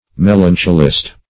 Search Result for " melancholist" : The Collaborative International Dictionary of English v.0.48: Melancholist \Mel"an*chol*ist\, n. One affected with melancholy or dejection.